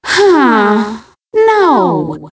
One of Rosalina's voice clips in Mario Kart Wii